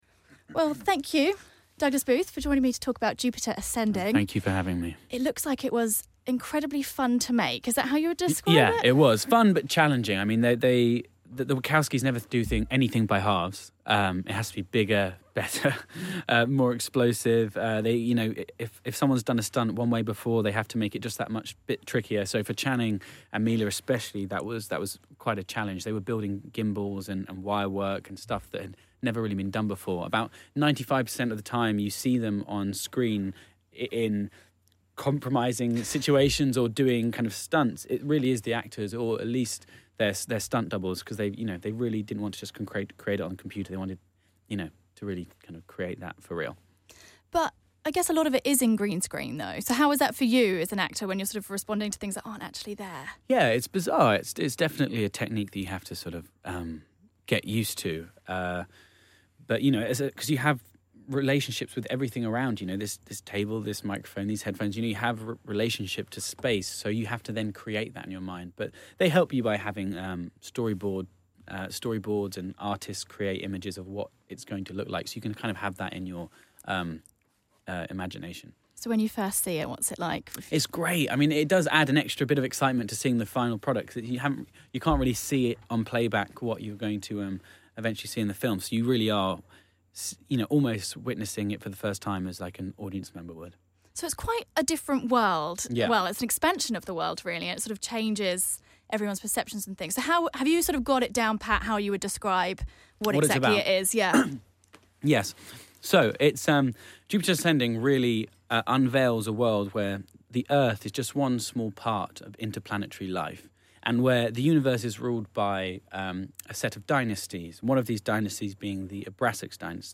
Jupiter Ascending: Douglas Booth Interview